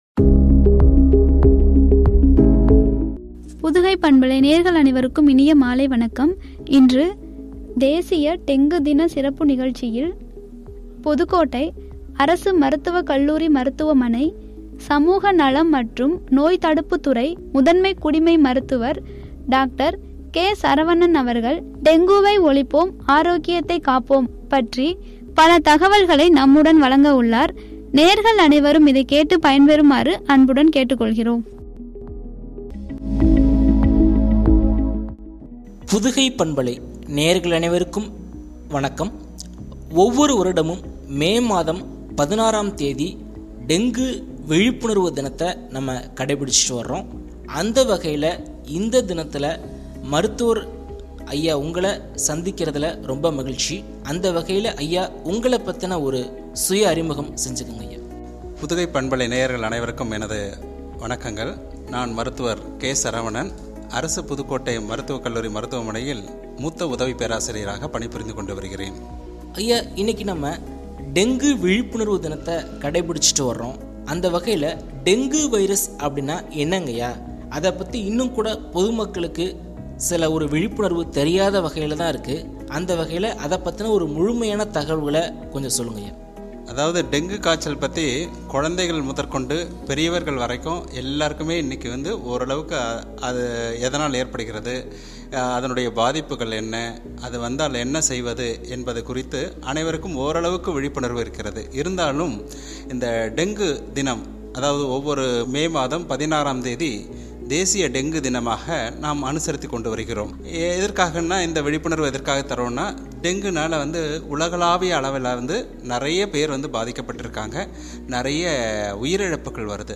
ஆரோக்கியத்தை காப்போம்” குறித்து வழங்கிய உரையாடல்.